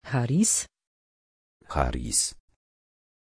Pronunciation of Charis
pronunciation-charis-pl.mp3